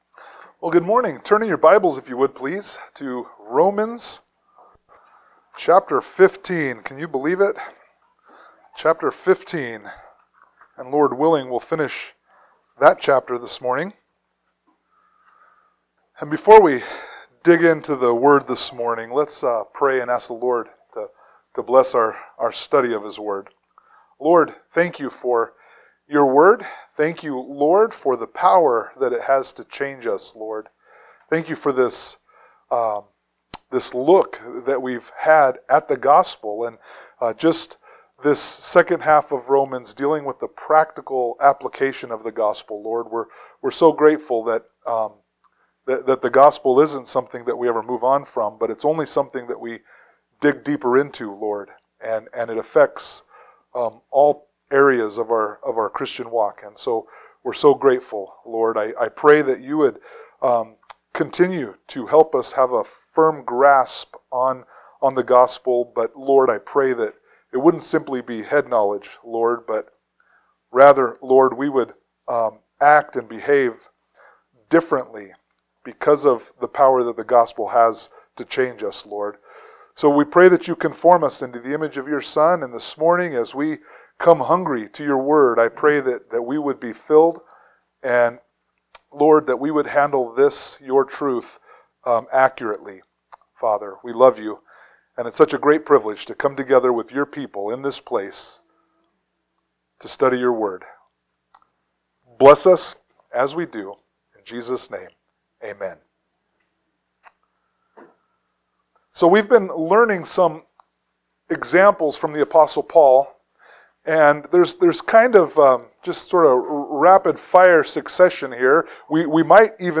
Romans 15:22-33 Service Type: Sunday Morning Worship « Romans 12:1-2